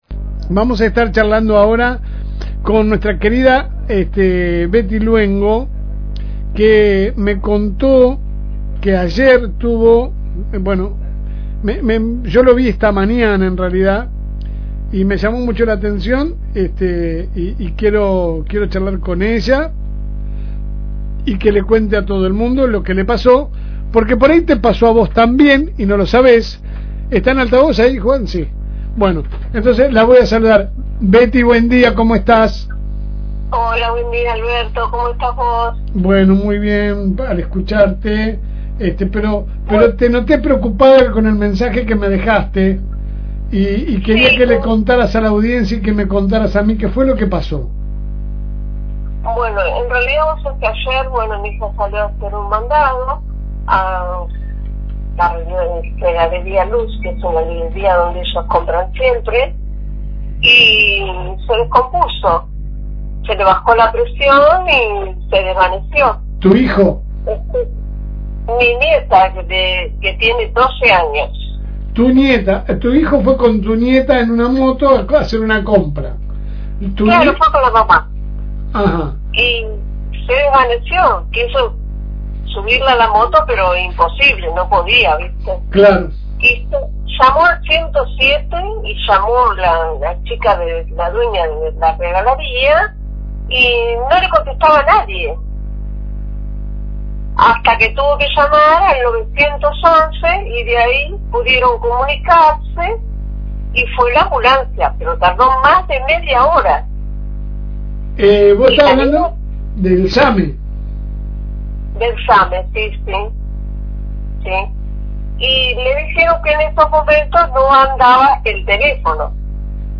Escucha a la vecina en el siguiente link de audio